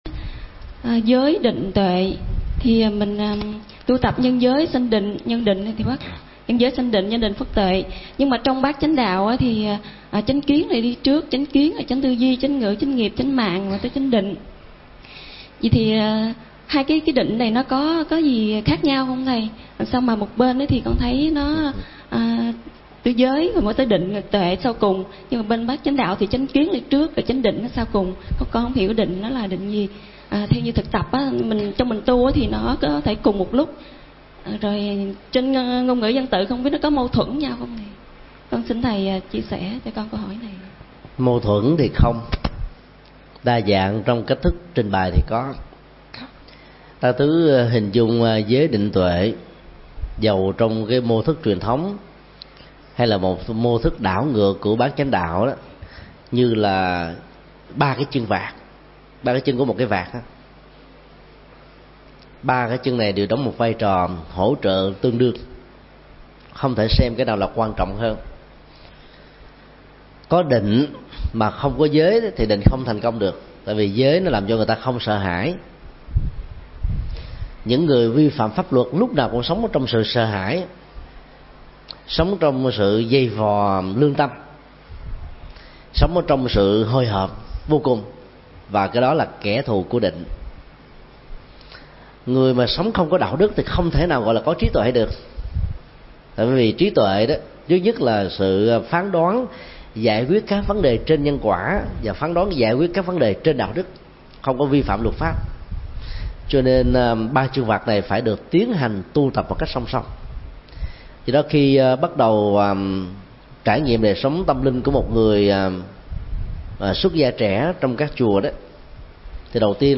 Vấn đáp: Giới định tuệ, Bát chánh đạo – Thích Nhật Từ